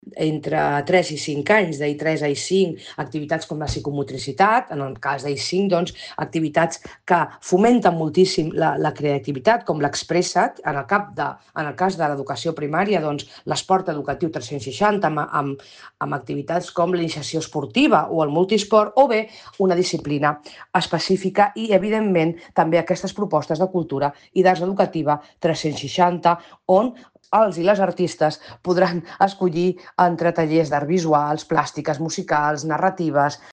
Soledad Rosende, regidora d'Ensenyament